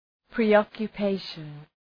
Προφορά
{prı,ɒkjə’peıʃən}